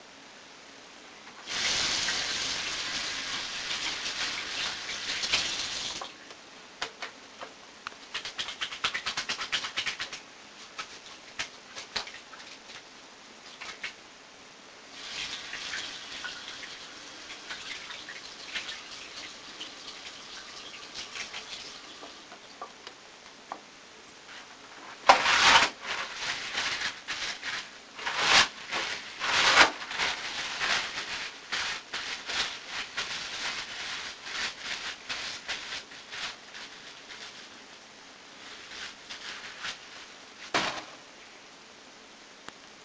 办公室 " 厕所02
描述：记录某人在办公室的卫生间里洗手。使用水，使用肥皂，再次使用水，用纸巾擦干手，将纸巾扔进垃圾桶。使用奥林巴斯VN480录音机录制。
Tag: 办公室 厕所 洗涤